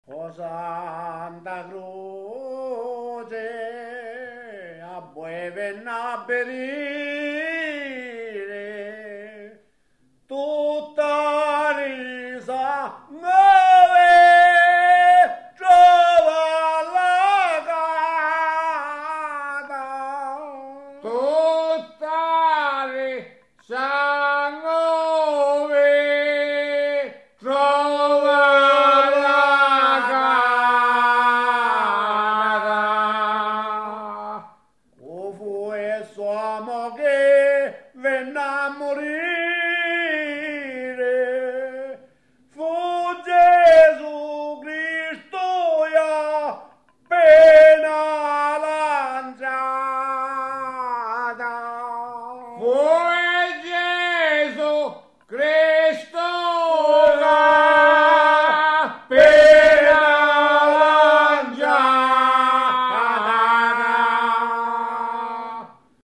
O Santa Cruci , Lamentazione popolare del Venerdì Santo